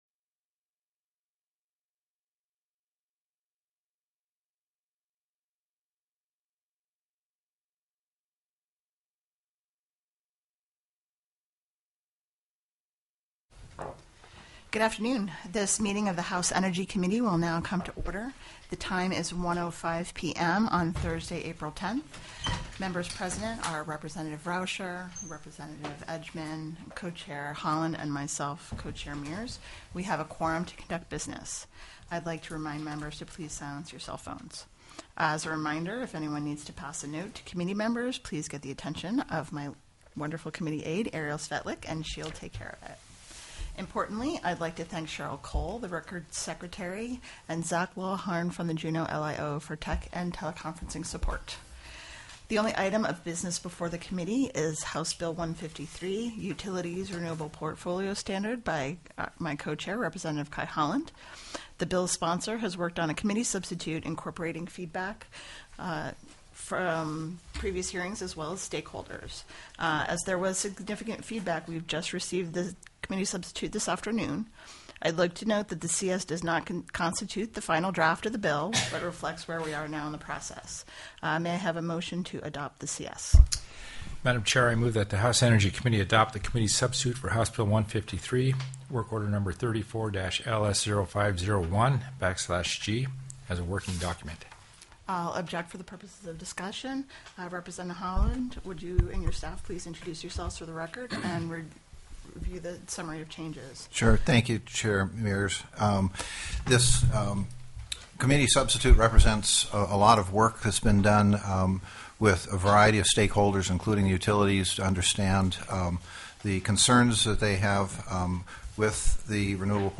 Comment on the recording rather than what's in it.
04/10/2025 01:00 PM House ENERGY ALASKA STATE LEGISLATURE HOUSE SPECIAL COMMITTEE ON ENERGY